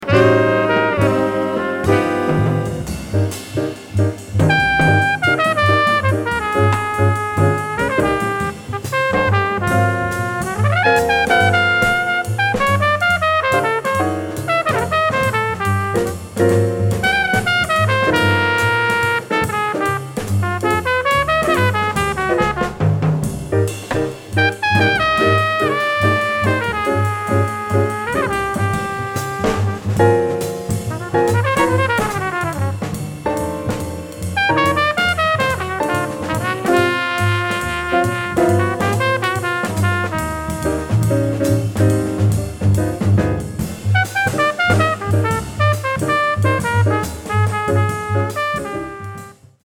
with a 12-measure form that's not quite a blues